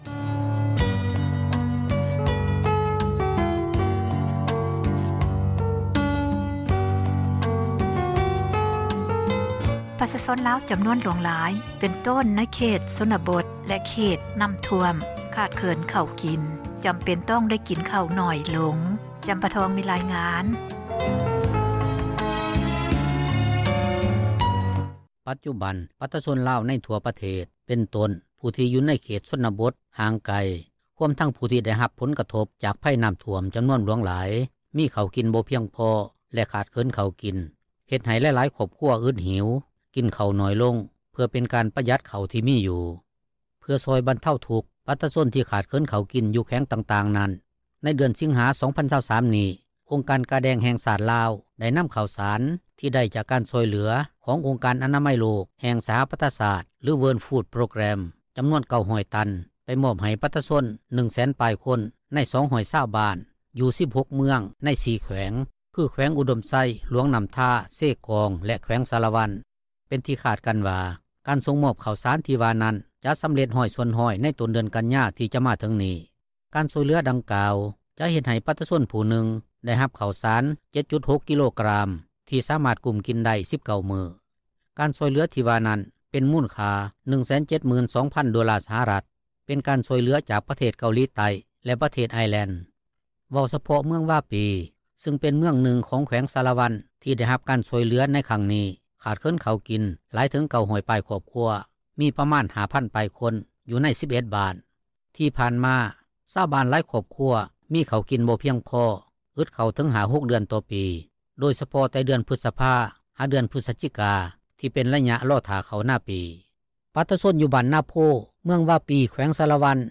ປະຊາຊົນ ຢູ່ບ້ານນາໂພ ເມືອງວາປີ ແຂວງສາຣະວັນ ເວົ້າຕໍ່ວິທຍຸ ເອເຊັຽ ເສຣີ ໃນມື້ວັນທີ 29 ສິງຫານີ້ວ່າ ປັດຈຸບັນ ປະຊາຊົນຫລາຍຄົນໃນເມືອງວາປີ ບໍ່ມີເຂົ້າພໍກິນແລ້ວ (ອຶດເຂົ້າແລ້ວ).